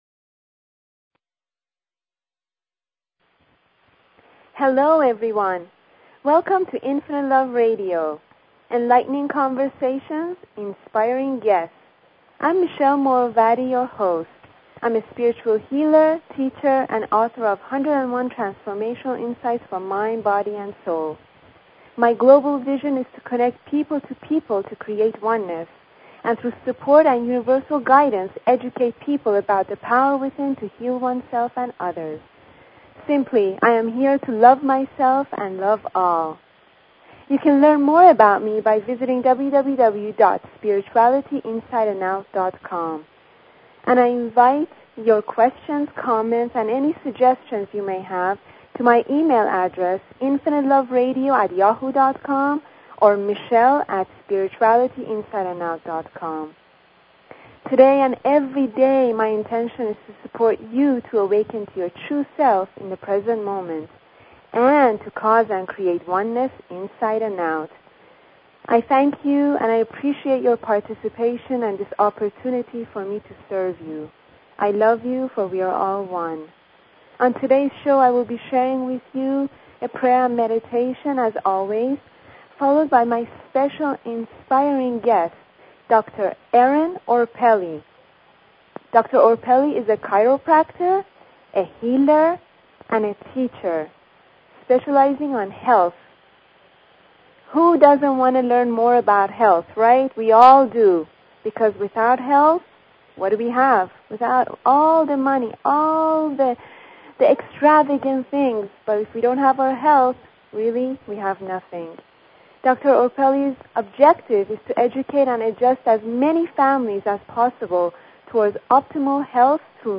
Talk Show Episode, Audio Podcast, Infinite_Love_Radio and Courtesy of BBS Radio on , show guests , about , categorized as